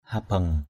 /ha-bʌŋ/ (t.) hư = gâté, hors d’usage. mata habeng mt% hb$ mắt hư = œil perdu. mata pajaih habeng mt% p=jH hb$ mộng hạt giống hư = le germe de la...